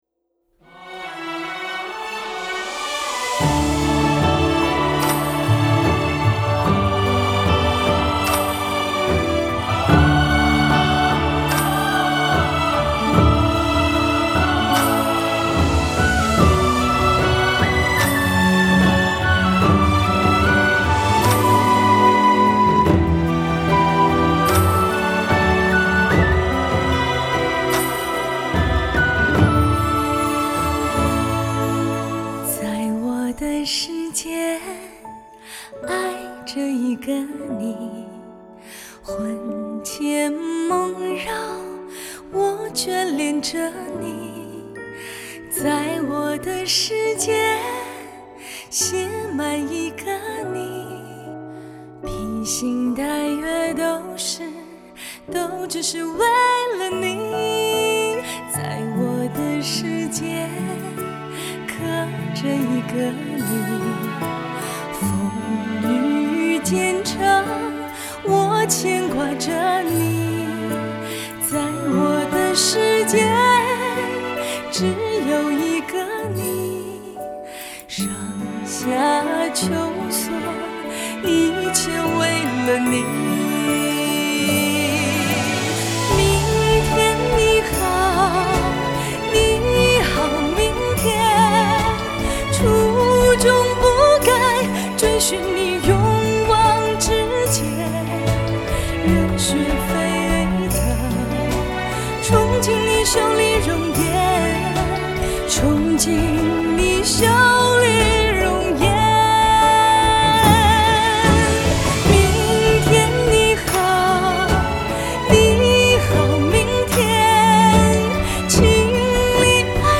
歌曲旋律优美，歌声婉转、娓娓道来